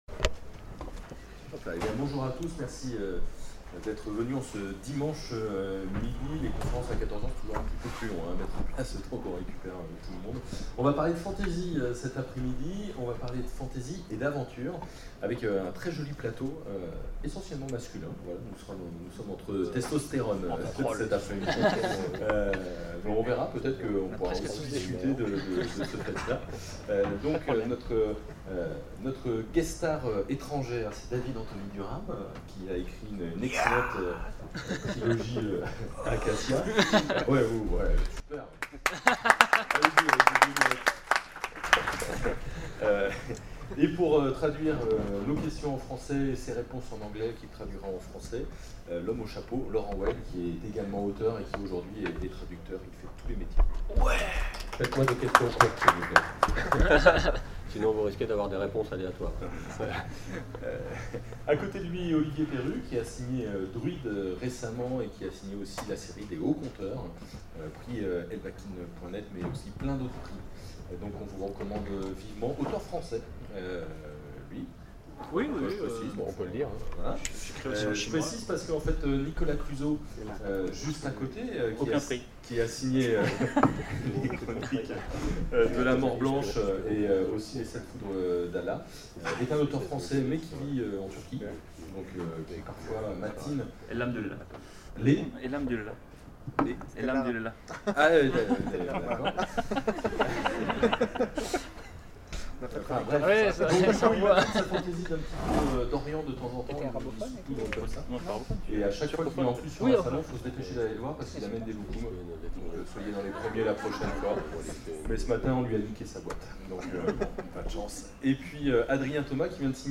Imaginales 2012 : Conférence La fantasy...